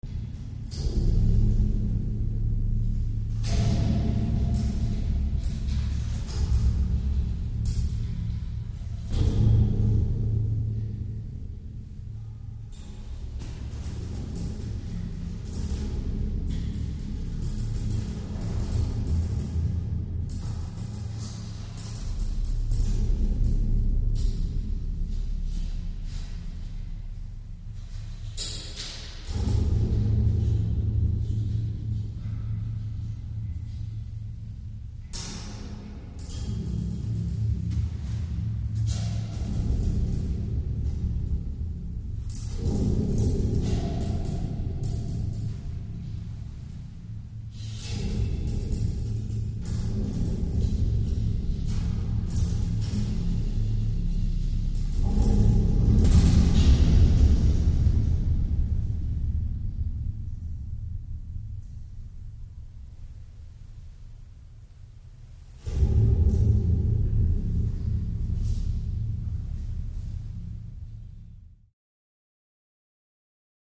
Echoing Room W Moving Metal Drums.